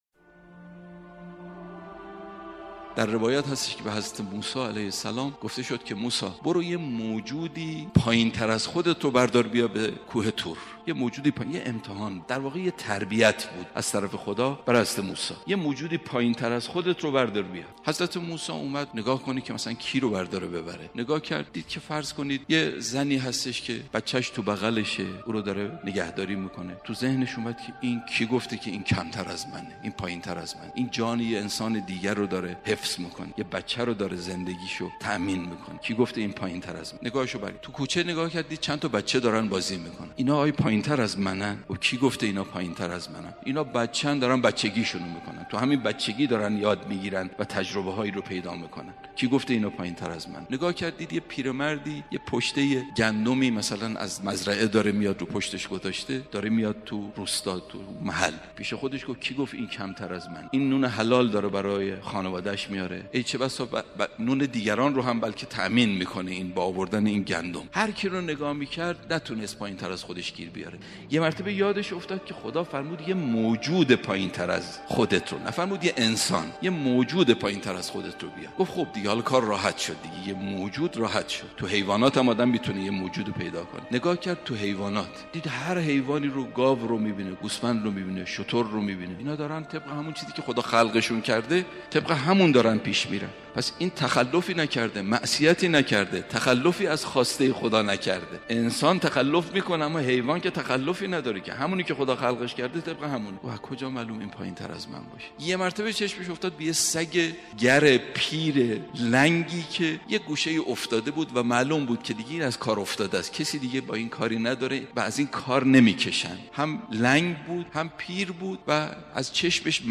خطیب ممتاز کشورمان